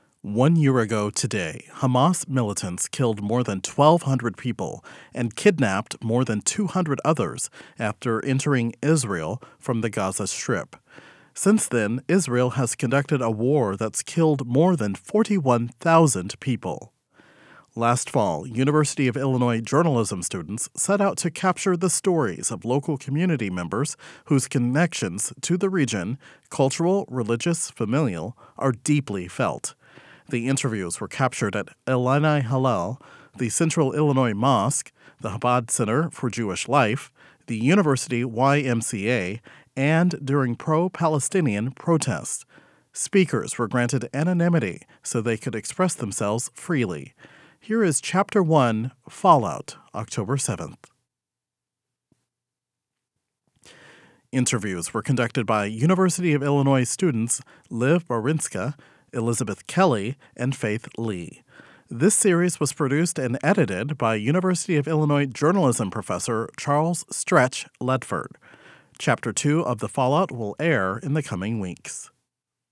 Speakers were granted anonymity so they could express themselves freely.